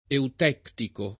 eutectico [ eut $ ktiko ]